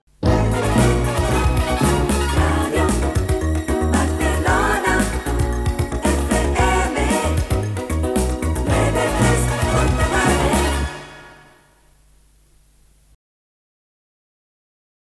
Indicatiu de l'emissora
Jingle